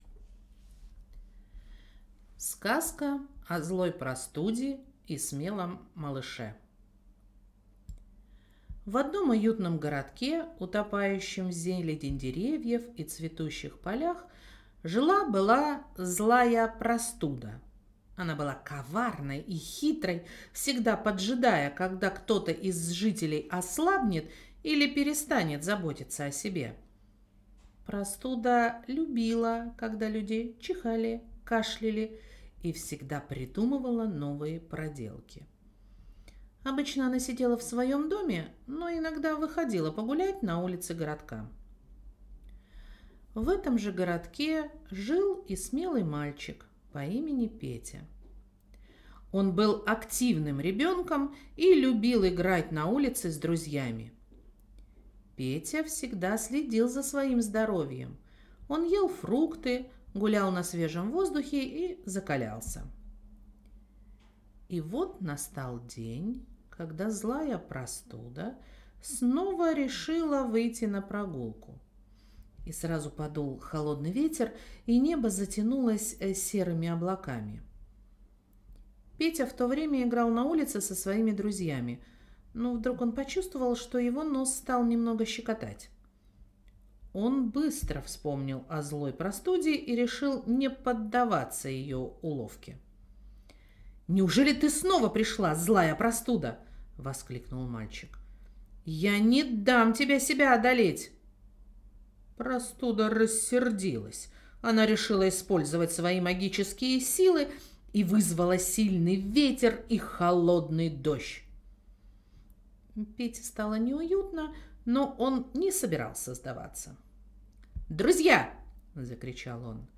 Аудиосказка о злой простуде и смелом малыше